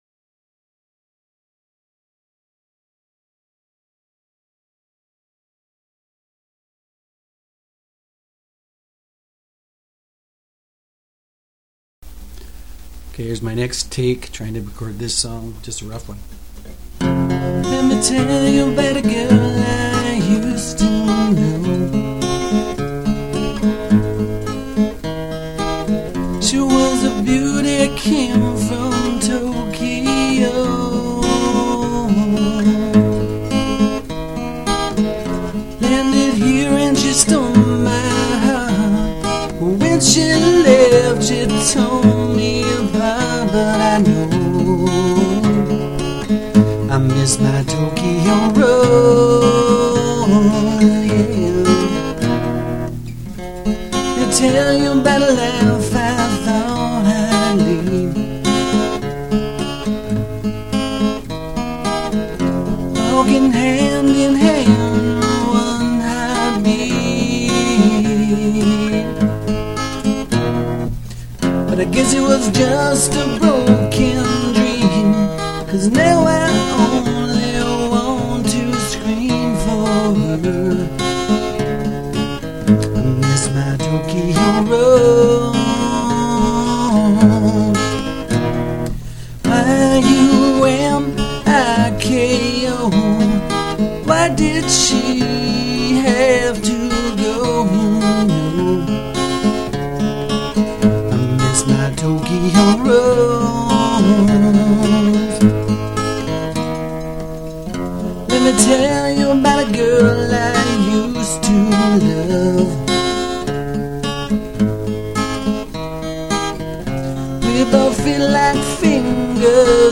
Folk
World music